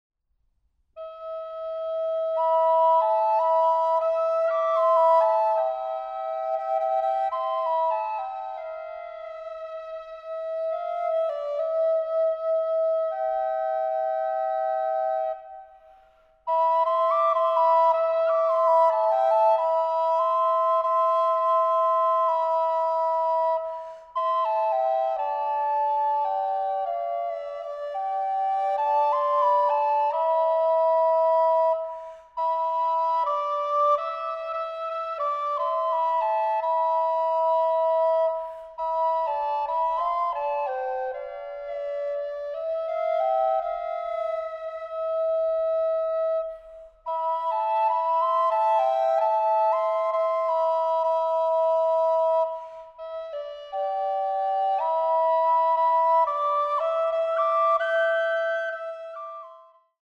Flöte